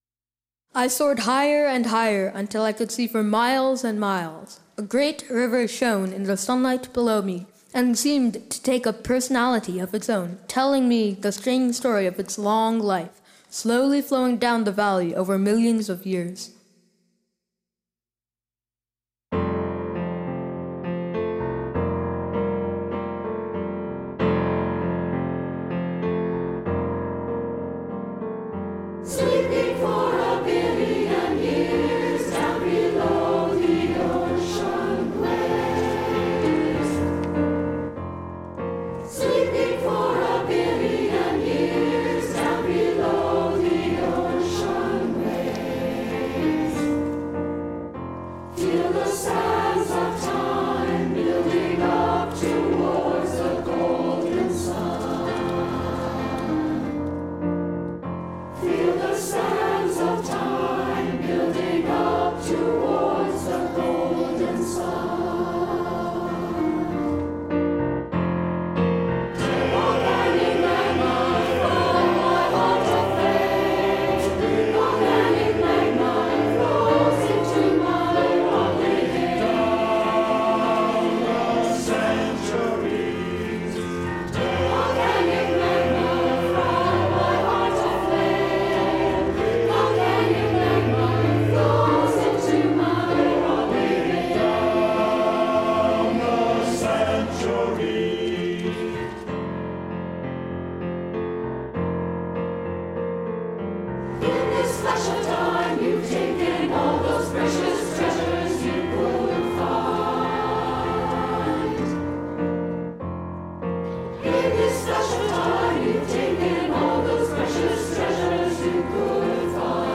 piano
Below are summaries and recordings of the songs of Powers of Ten as performed by the 2014 NCFO Festival Chorus.
• Song of the Tamar Valley – At the level of landscape, the Tamar itself (the river that runs along the border between Devon & Cornwall, near the composer's home in southwestern England) sings a stately anthem glorifying its billion-year history, and of the power of Nature to triumph eventually over our puny efforts to mine the river's banks.